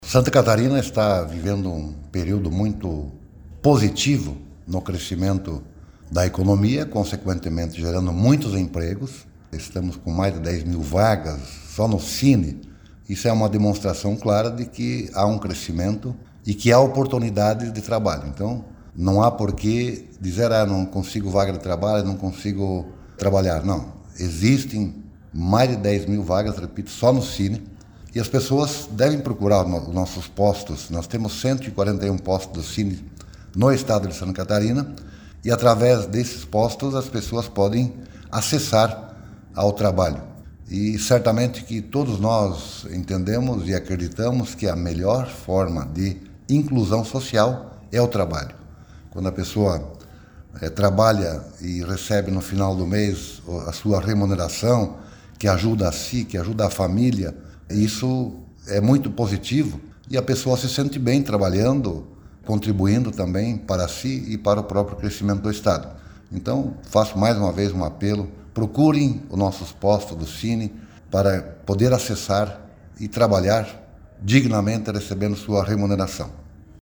Para secretário de Estado da Indústria, Comércio e Serviço, Silvio Dreveck, o mercado de trabalho está aquecido e ele orienta as pessoas a buscarem um oportunidade porque elas existem hoje em Santa Catarina:
SECOM-Sonora-secretario-da-SICOS-19.mp3